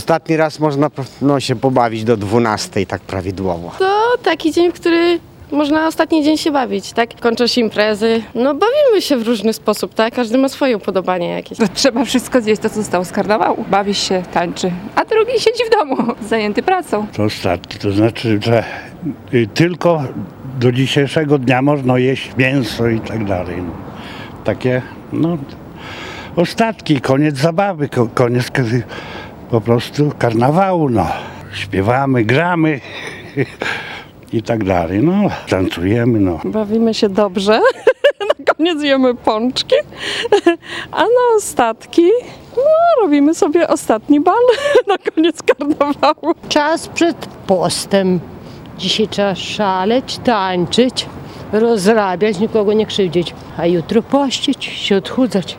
O sposobie na spędzenie ostatniego dnia karnawału rozmawialiśmy też z mieszkańcami Suwałk.
suwalczanie-o-karnawale.mp3